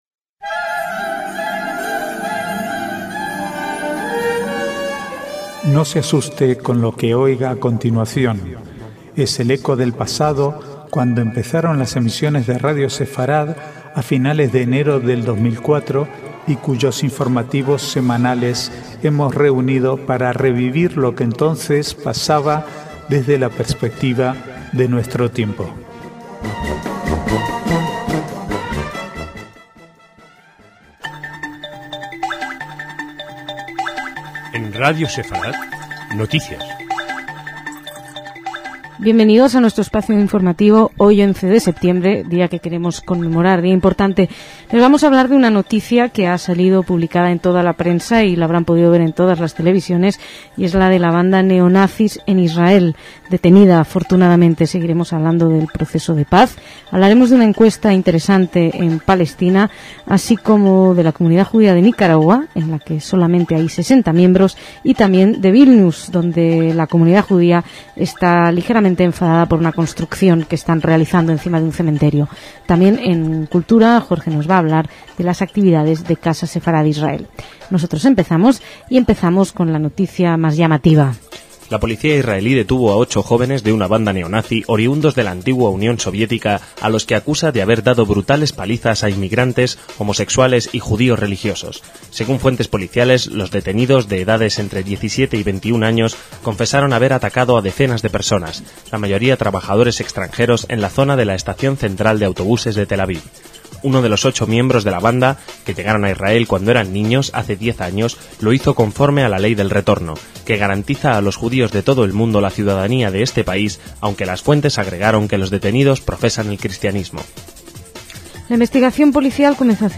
Archivo de noticias del 11 al 18/9/2007